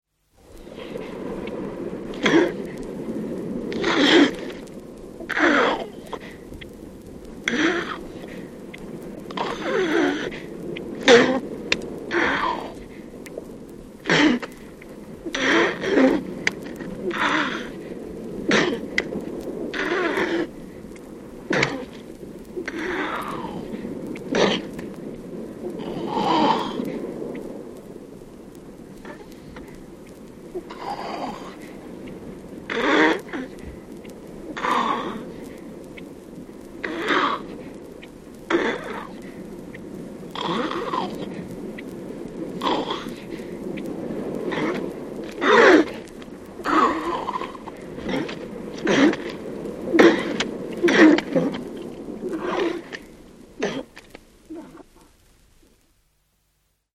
В коллекции — рычание, кряхтение и другие характерные голосовые реакции этих морских животных.
Длинномордый тюлень издает удивительные звуки в дикой природе